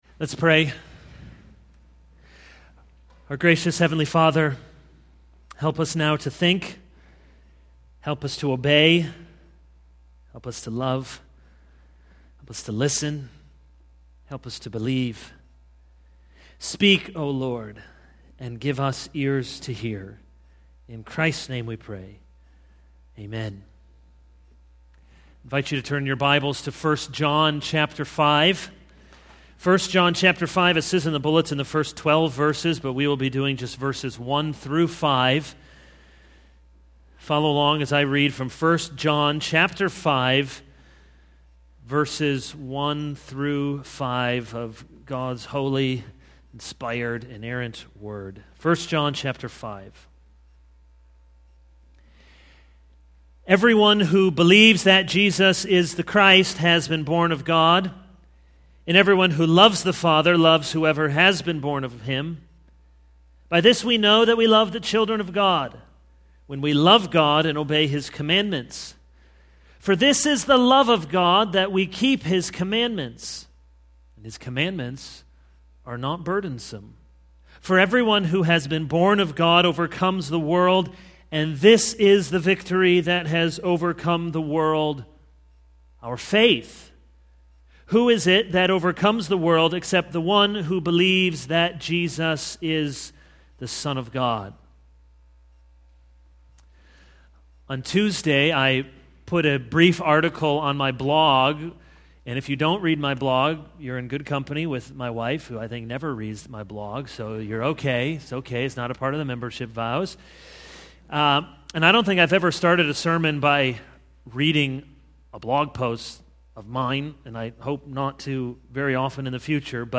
This is a sermon on 1 John 5:1-5.